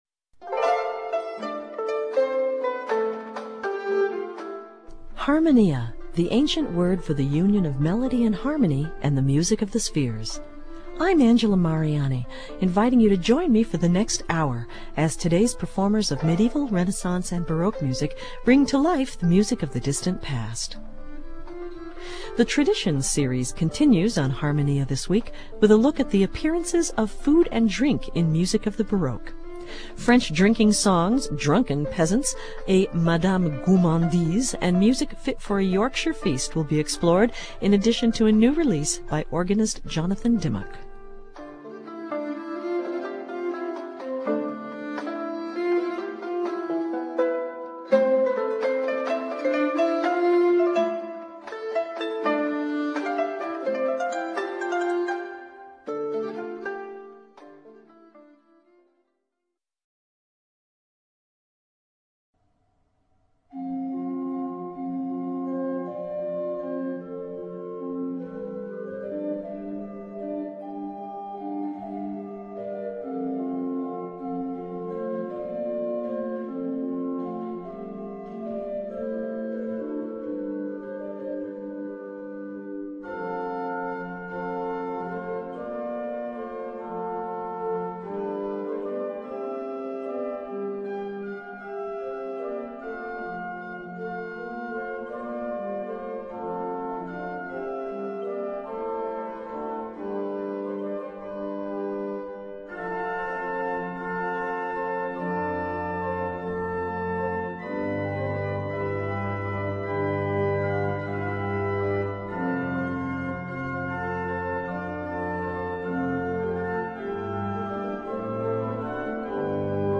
organist